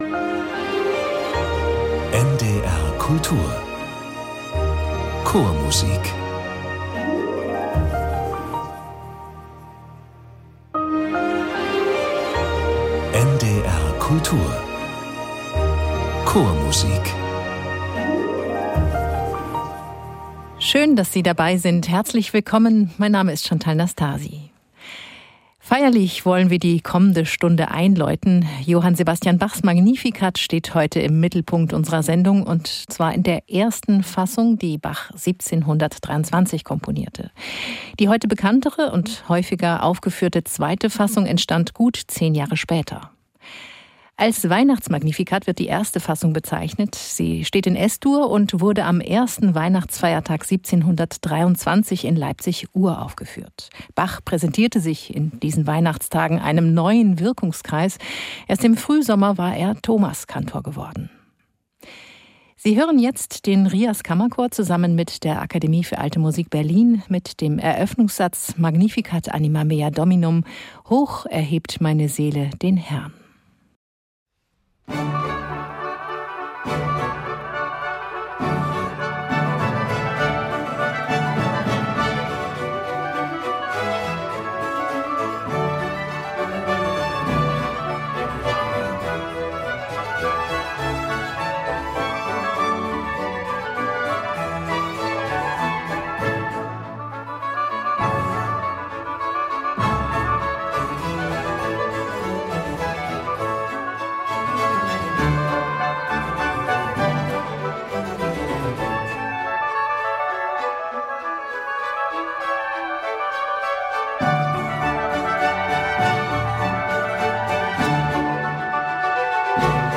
Bachs "Weihnachtsmagnificat" und Händels "Utrecht Te Deum" ~ Chormusik - Klangwelten der Vokalmusik entdecken Podcast
Zwei feierliche Werke, mit denen sich Bach und Händel neuen Wirkungskreisen präsentierten, gesungen vom RIAS Kammerchor.